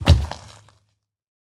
assets / minecraft / sounds / mob / zoglin / step1.ogg